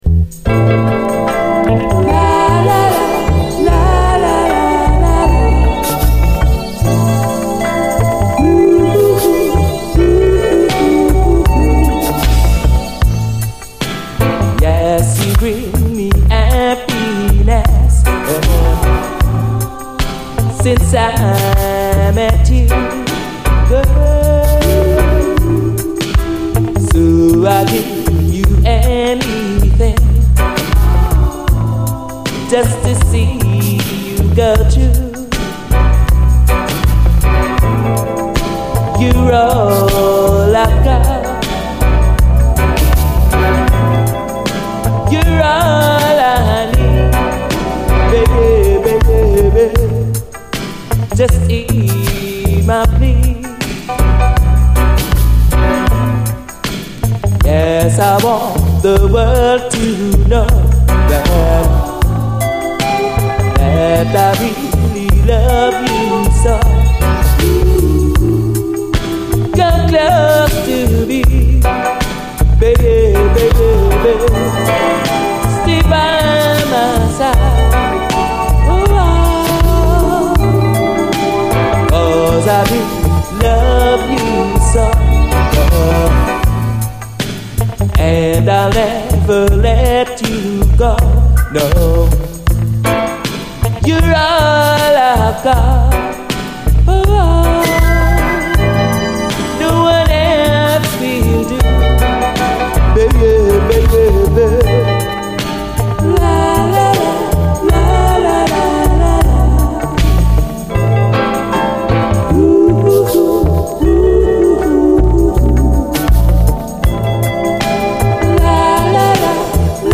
REGGAE
キラーな極上メロウ・ダビー・ラヴァーズ！空間的なシンセ使いとダビーな抜き差しが最高！